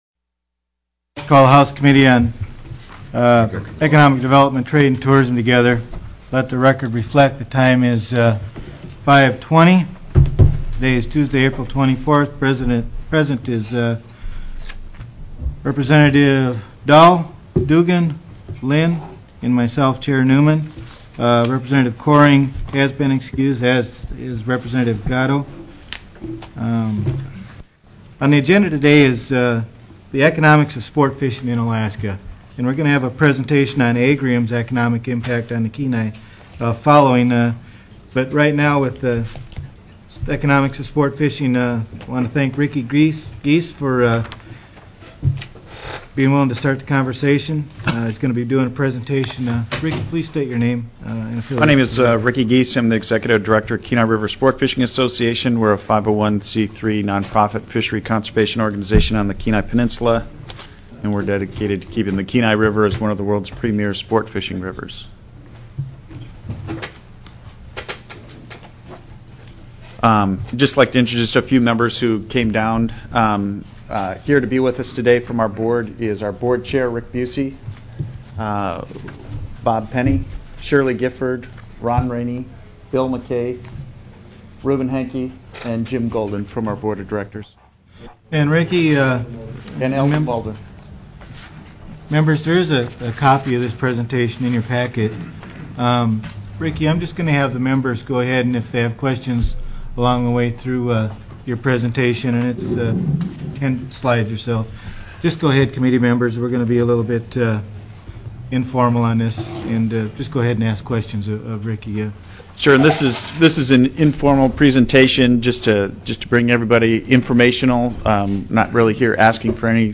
04/24/2007 05:15 PM House ECONOMIC DEV., TRADE, AND TOURISM
+ The Economics of Sport Fishing TELECONFERENCED